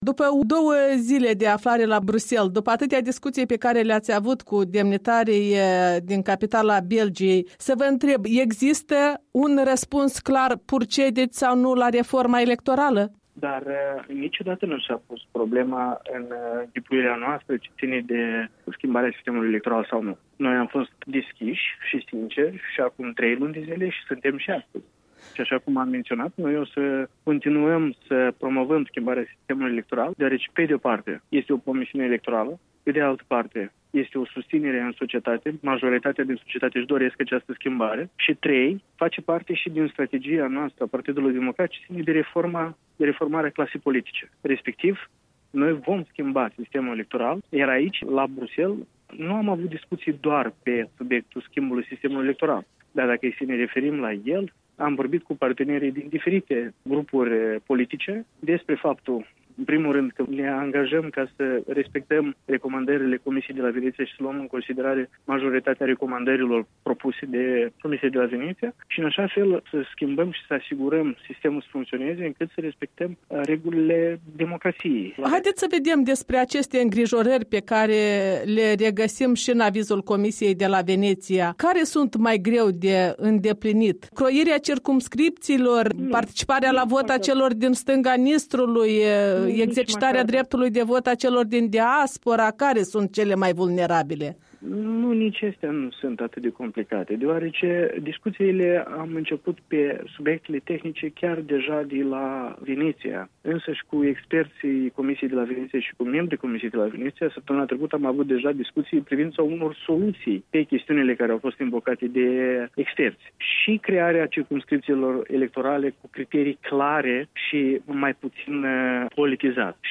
Președintele Parlamentului de la Chișinău: declarații la întoarcerea de la Bruxelles.